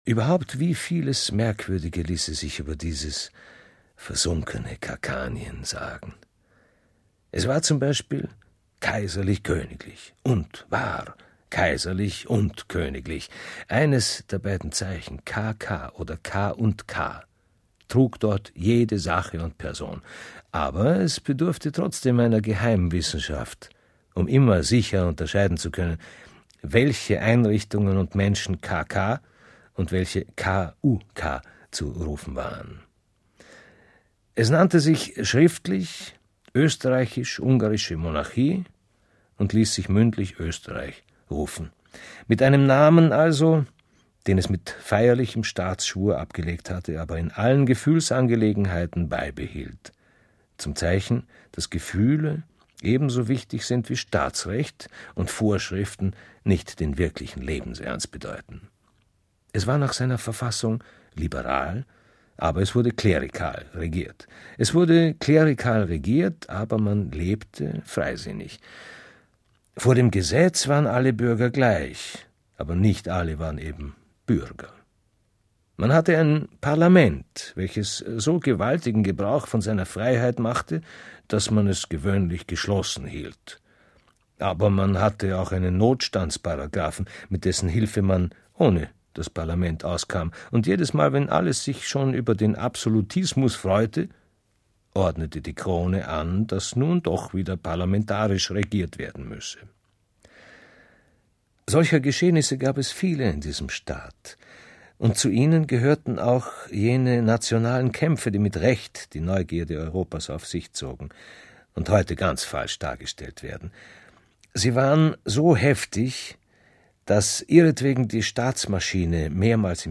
H�rb�cher